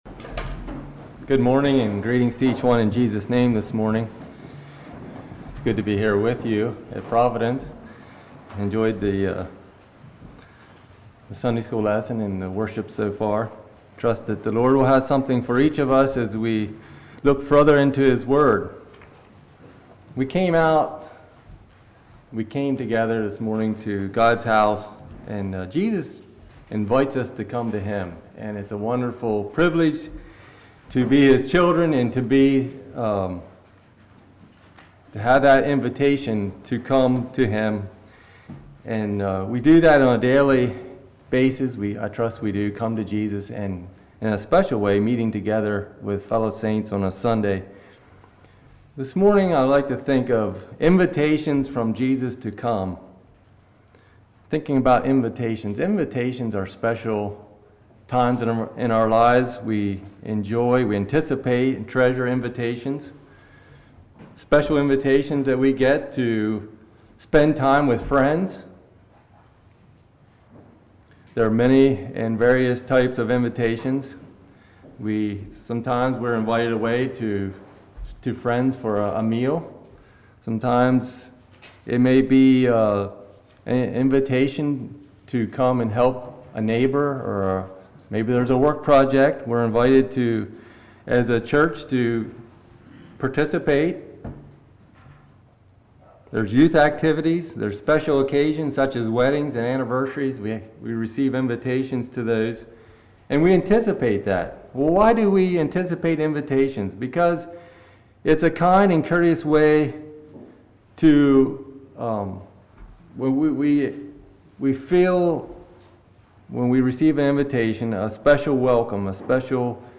Teaching on the invitations of Jesus throughout the scripture. Categories: General , Evangelistic